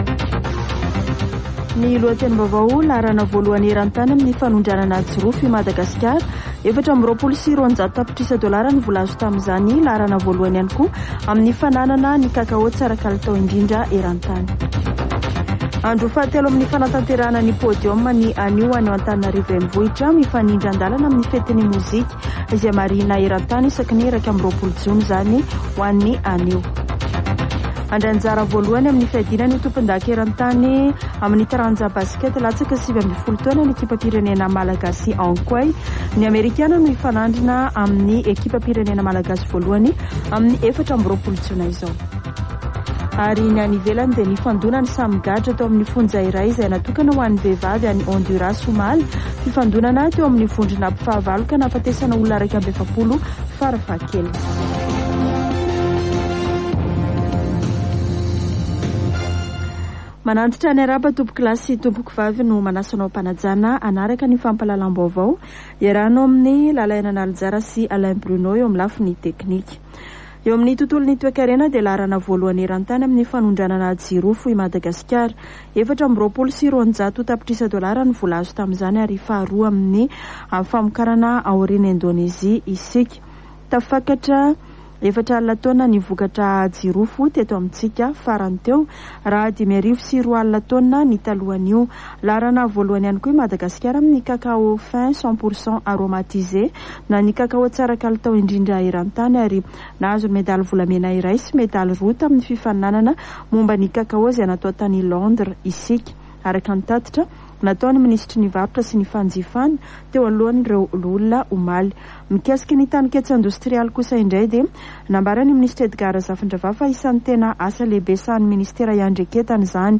[Vaovao antoandro] Alarobia 21 jona 2023